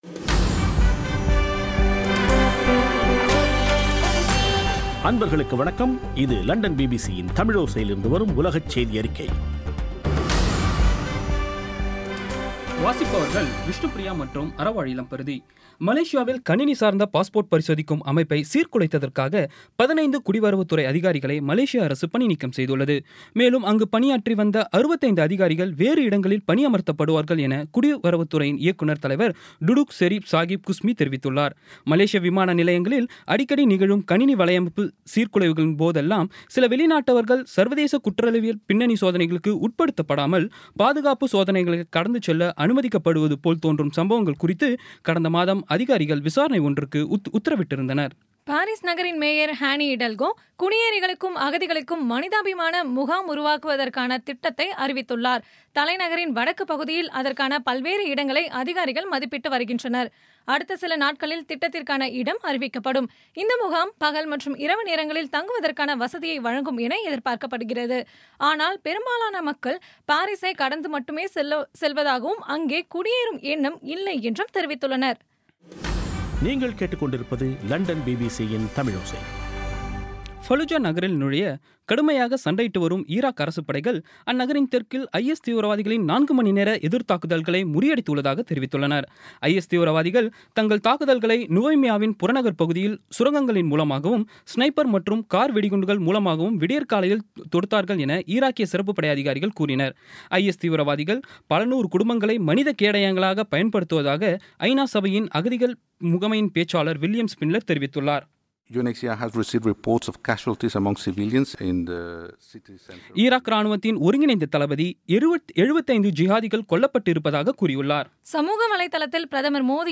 இன்றைய பி பி சி தமிழோசை செய்தியறிக்கை (31/05/2016)